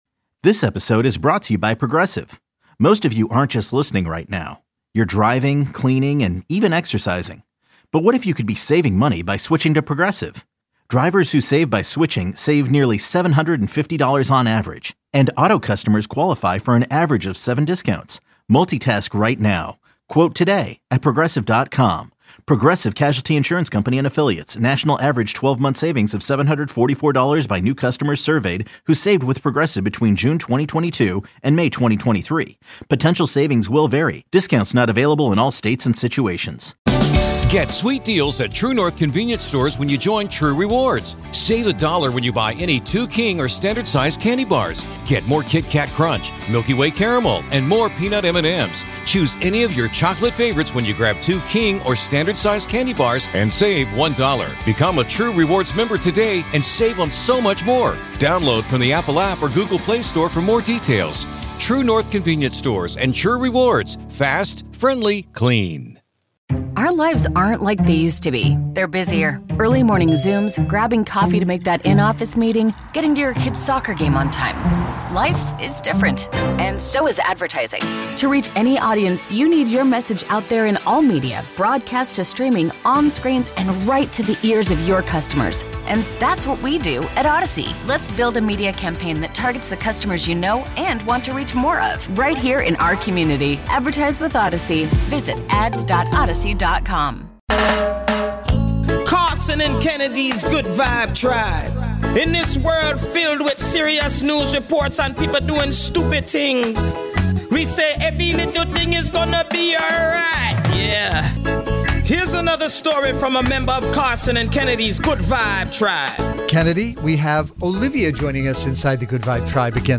The show is fast paced and will have you laughing until it hurts one minute and then wiping tears away from your eyes the next.